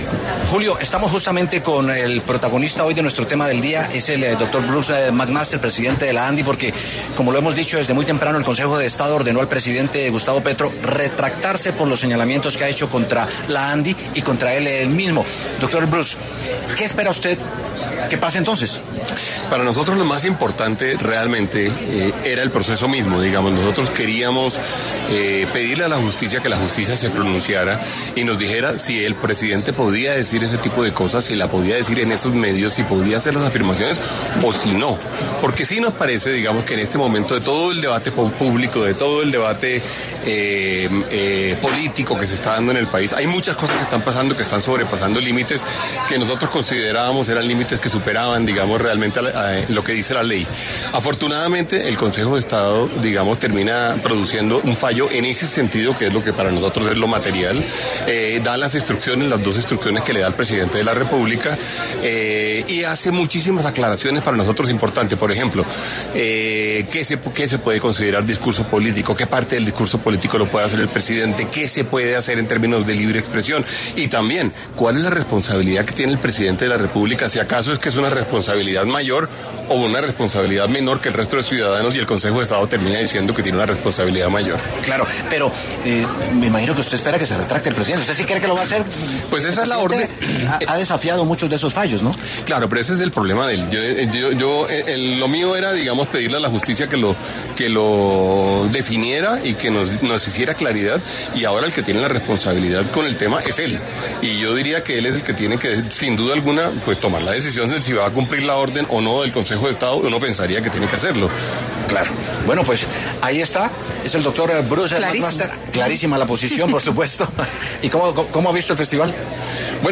Bruce Mac Master, presidente de la Asociación Nacional de Empresarios de Colombia, conversó con La W acerca de la decisión del Consejo de Estado que obliga al presidente de la República, Gustavo Petro, a eliminar un mensaje en X al considerar que vulneró el derecho fundamental al buen nombre del presidente de la Andi.